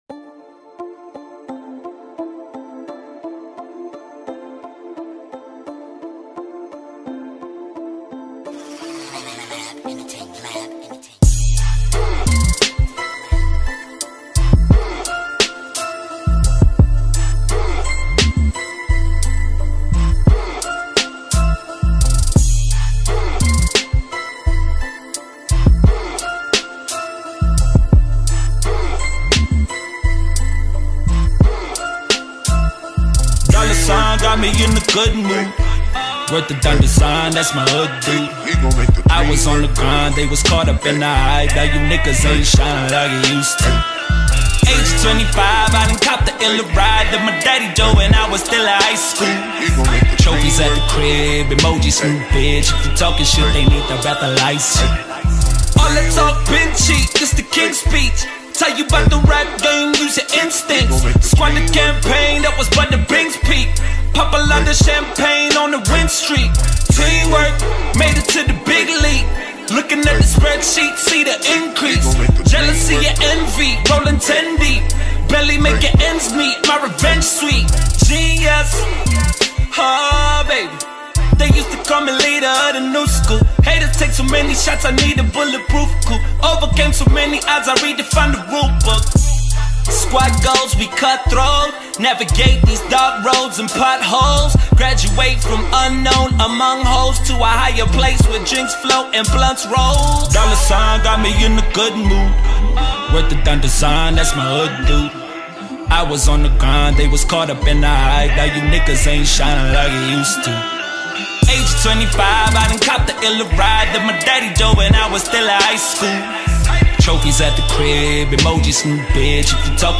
South African rapper
hip-hop